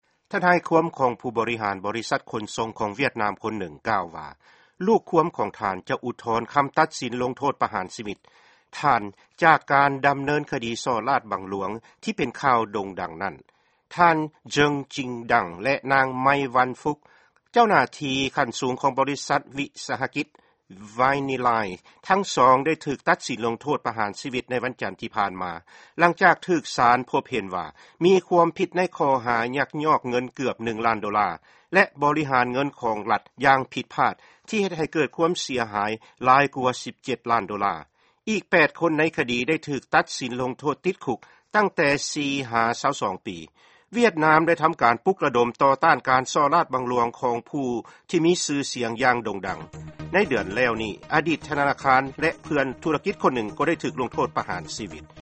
ຟັງຂ່າວ ປະເທດຫວຽດນາມ